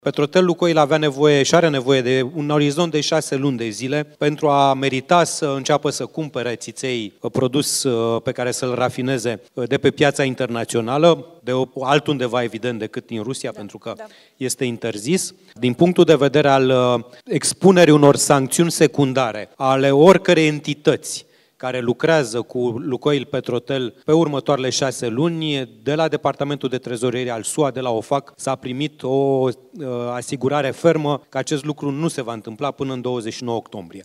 Secretarul de stat de la Ministerul Energiei, Cristian Bușoi: „Petrotel – Lukoil are nevoie de un orizont de şase luni de zile pentru a merita să înceapă să cumpere ţiţei”